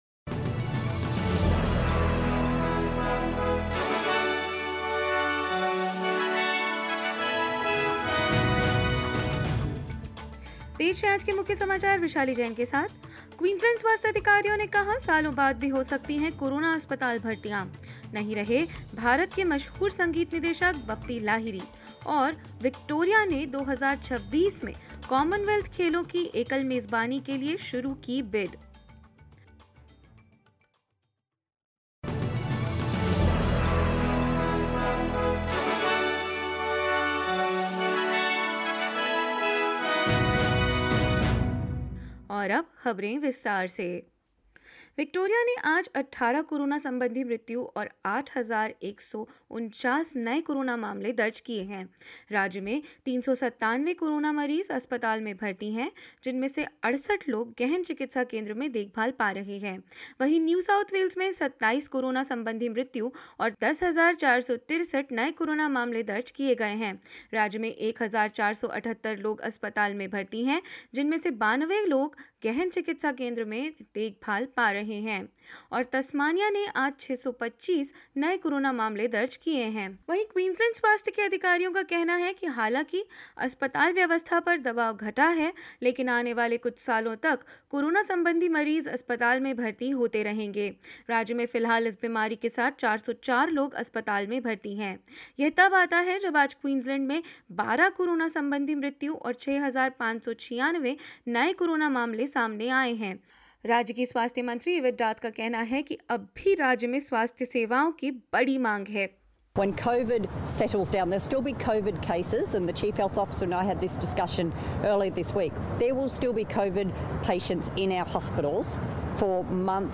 hindi_news_1602.mp3